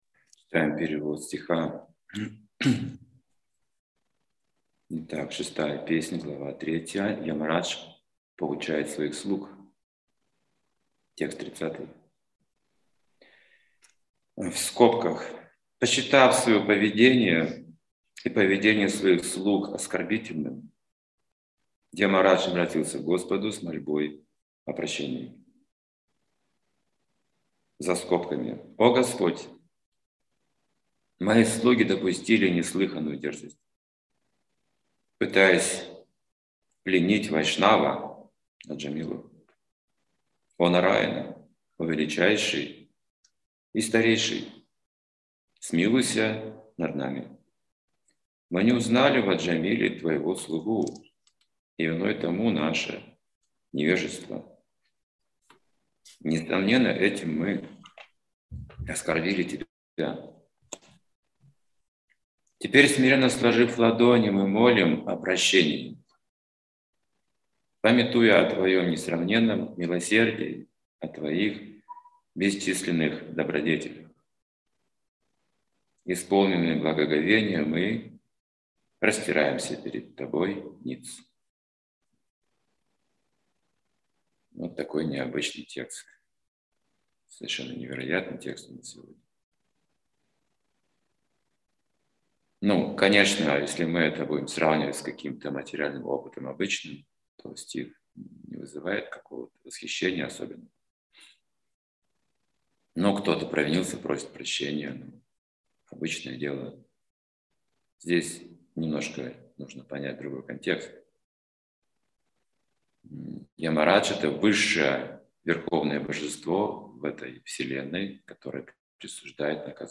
Как не грешить? 35:43 – Вопросы и ответы 38:44 – Наказание от Сверхдуши и от Бога смерти 57:14 – Наставления и советы.